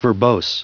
Prononciation audio / Fichier audio de VERBOSE en anglais
Prononciation du mot verbose en anglais (fichier audio)
verbose.wav